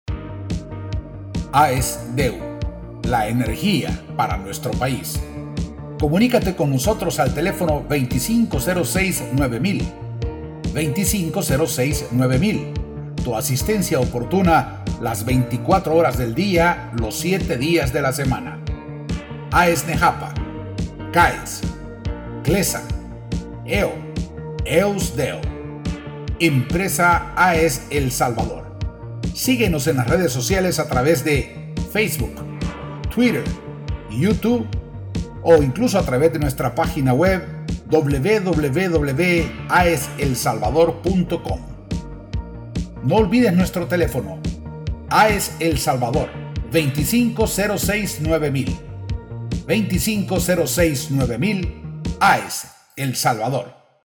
Spanish native speaking voice over, with a Neural Tone.
Sprechprobe: Werbung (Muttersprache):